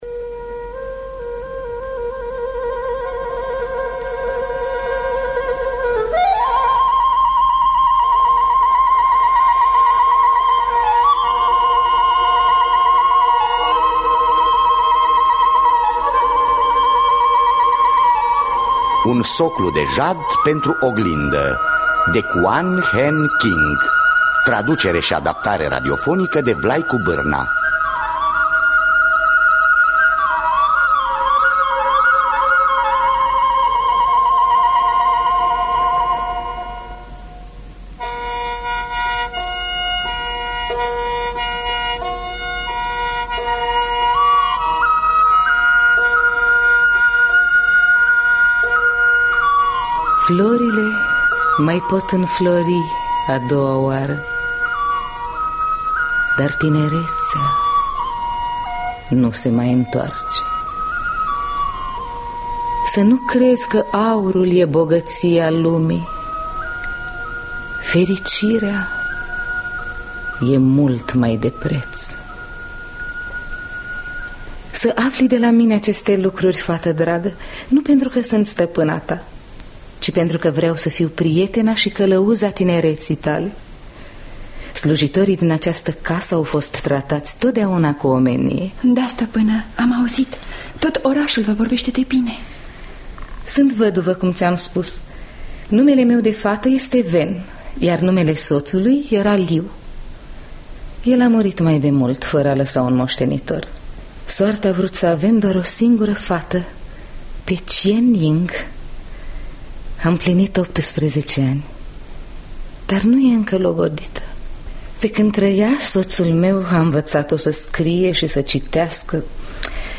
Adaptarea radiofonică de Vlaicu Bârna.